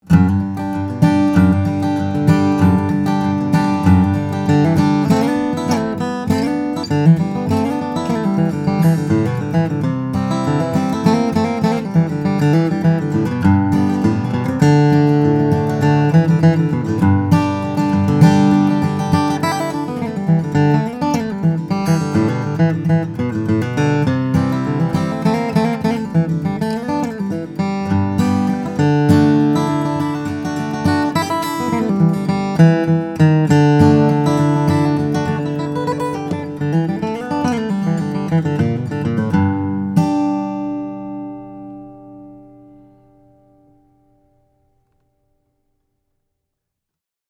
The Cadillac of Bluegrass Cannons, the Martin Dreadnaught is the icon of big-bodied, big-voiced flatpicking guitars–and this 2012 D-28 Marquis is a contender for the title. When you put pick to string, the result is instant bliss: the East Indian Rosewood back and sides and Adirondack Spruce top combine to give this D-28 a monstrous…